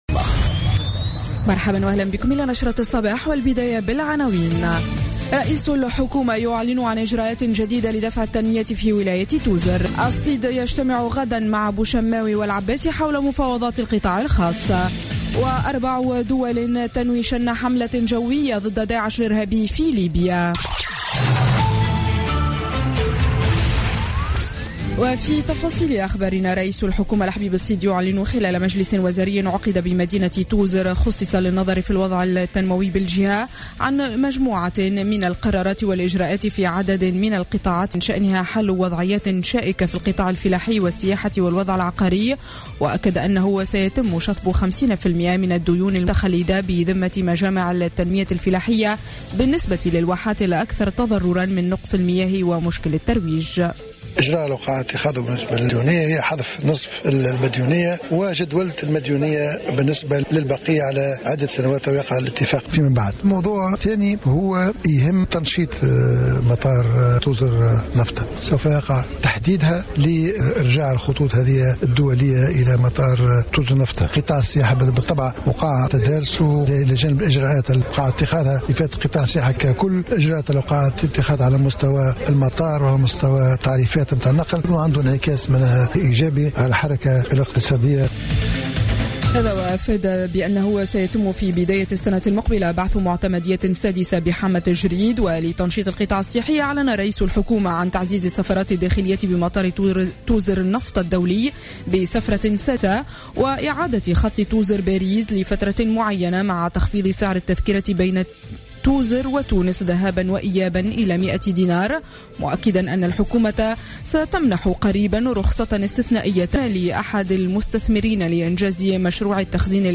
نشرة أخبار السابعة صباحا ليوم السبت 26 ديسمبر 2015